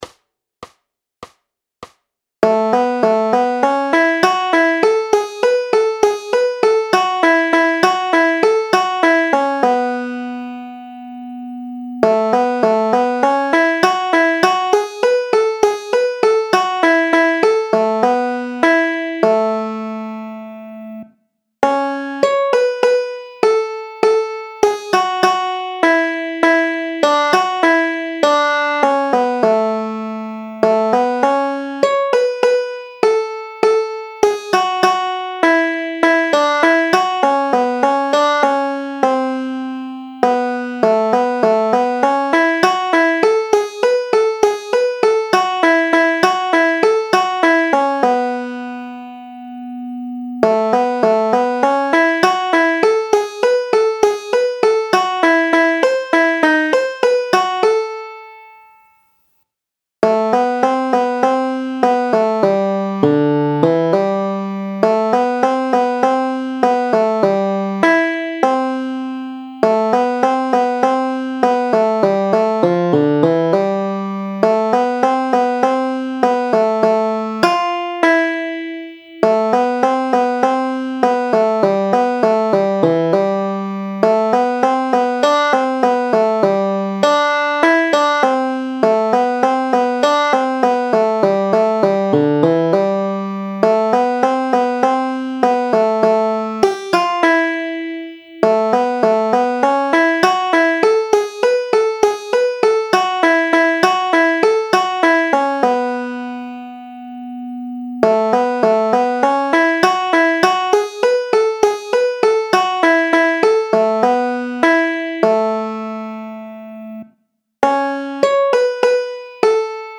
Noty, tabulatury na pětistrunné banjo.
Aranžmá Noty, tabulatury na banjo
Hudební žánr Klasický